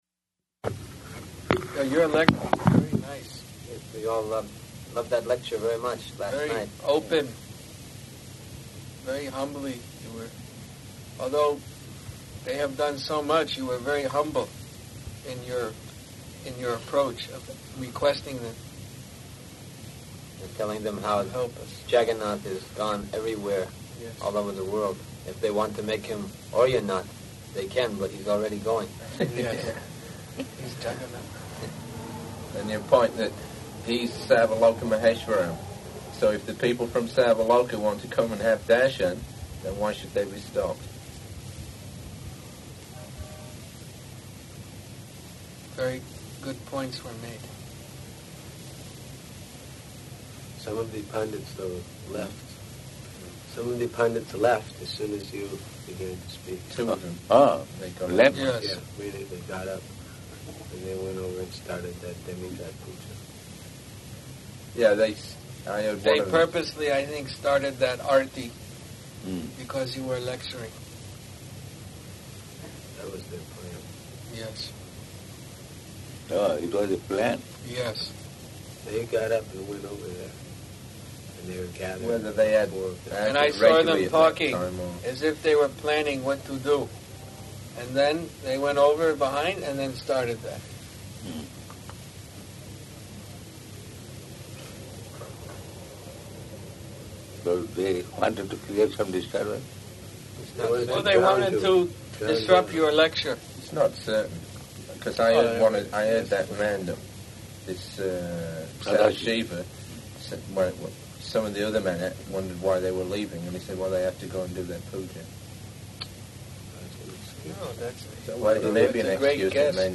Room Conversation
Location: Bhubaneswar